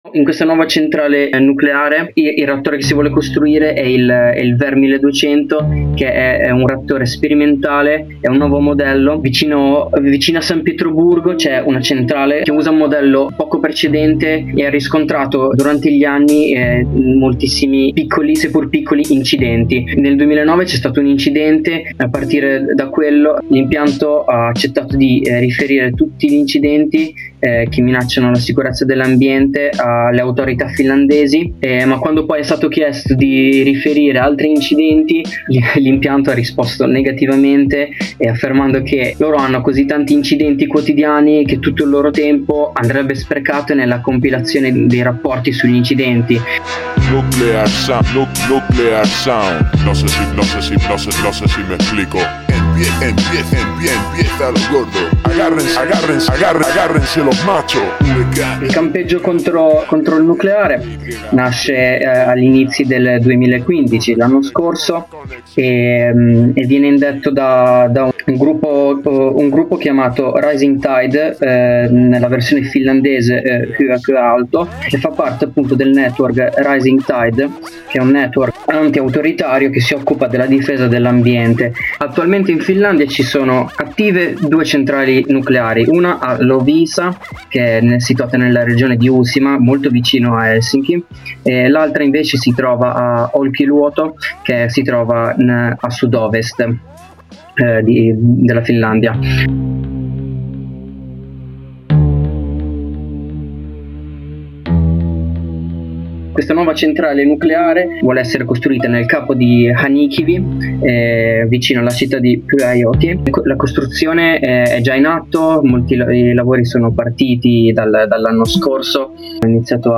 Un amico più che di passaggio ci ragguaglia brevemente su questo frammento di lotta al nucleare in terra nordica.